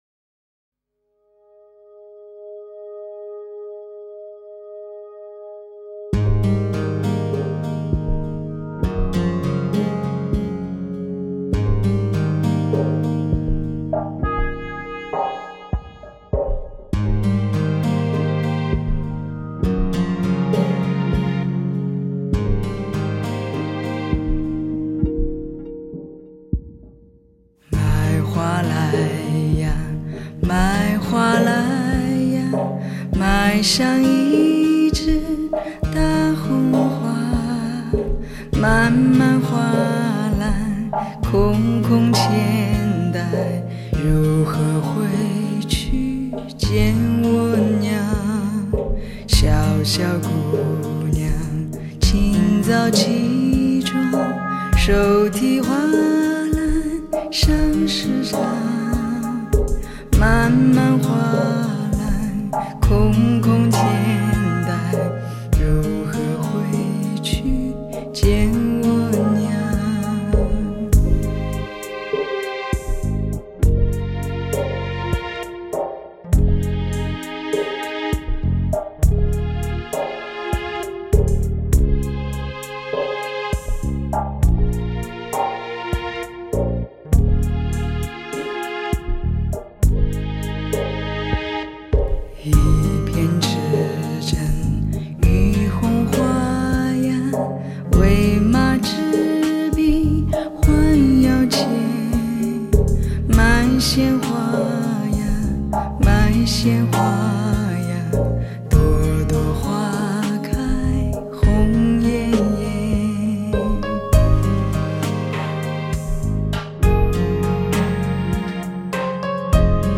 吉他
贝司
口琴